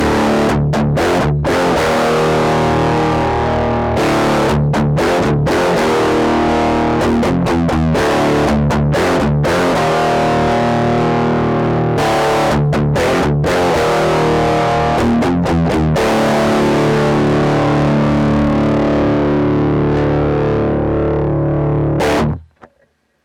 SubDecay Blackstar Klangbeispiele
Alle Klangbeispiele wurden mit einer ESP Eclipse II mit aktiven Humbucker in Drop D aufgenommen. Bei den Aufnahmen, bei denen sich Tone respektive Girth ändern, geht es im Takt von der Nullstellung auf Mitte dann auf Voll und wieder zurück.